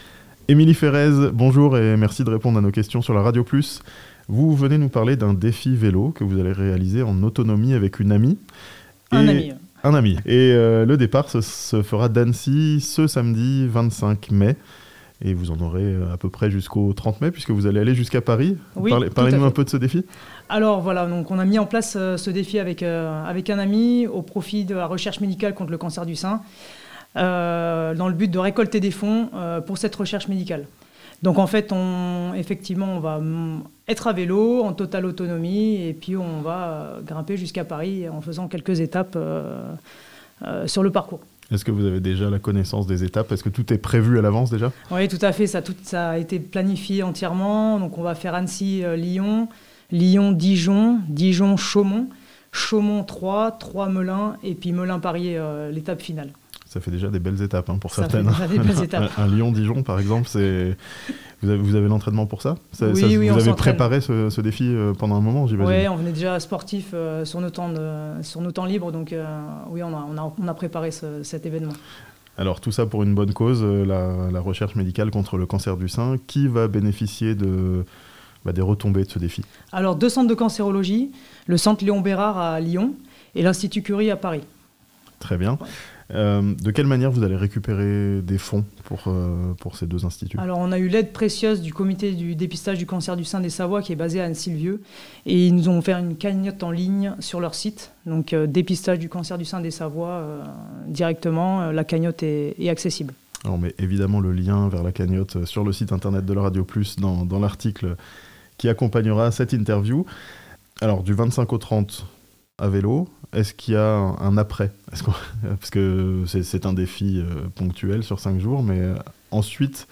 D'Annecy à Paris à vélo, pour la lutte contre le cancer (interview)